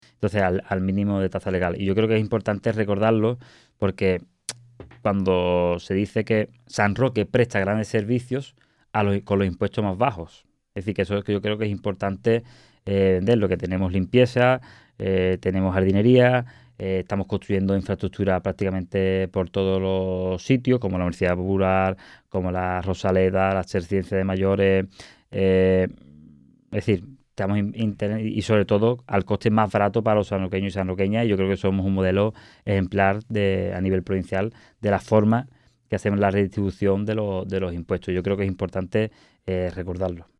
Canal San Roque cuenta de nuevo con la sección semanal del teniente de alcalde Fernando Vega, y donde el concejal repasa novedades de las delegaciones que ostenta: Economía y Hacienda, Universidad Popular, Empleo y ahora también Deportes, tras la última reestructuración con la salida del Equipo de Gobierno de Mónica Córdoba.
TOTAL_FERNANDO_RADIO.mp3